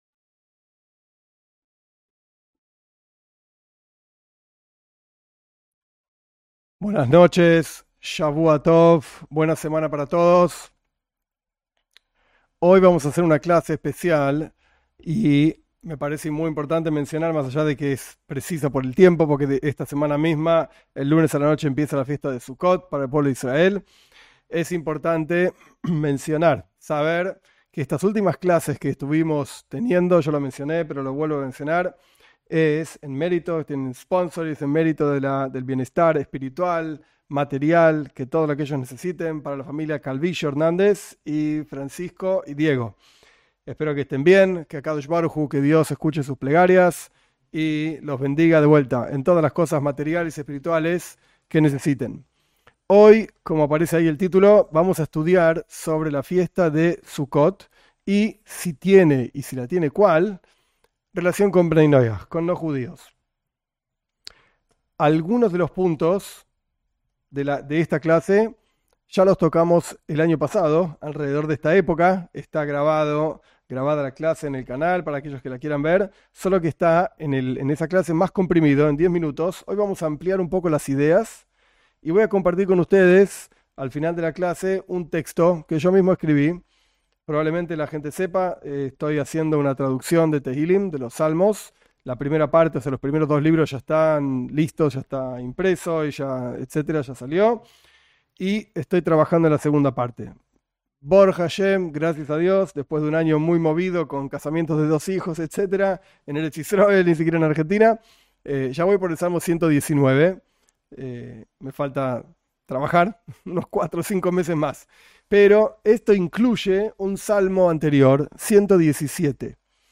En esta clase se explica qué relación tienen los no judíos con la fiesta judía de Sucot. Se ofrece una guía para saber qué hacer, qué no hacer y por qué.